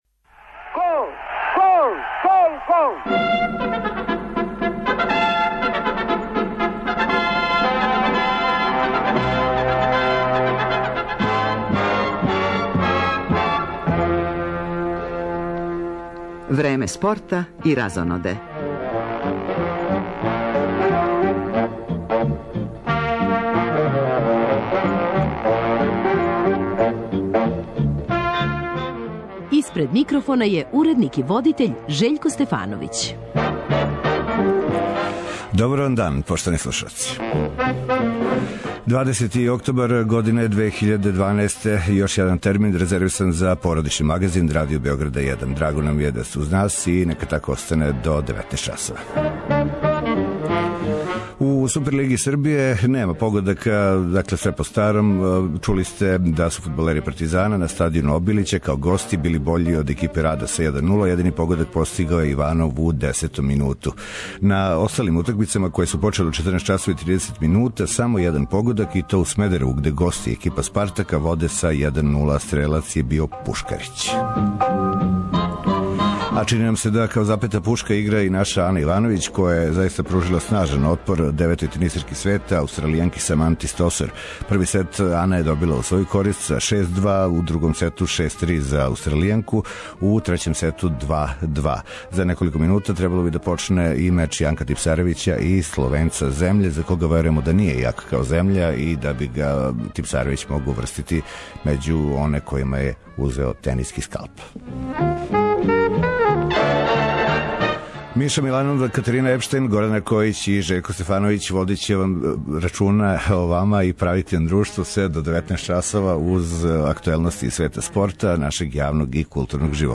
Куда иде српски фудбал и хоће ли нас у догледно време неки наш клуб или репрезентација изненадити каквим-таквим успехом? То је тек једна од тема овог издања породичног магазина Радио Београда 1.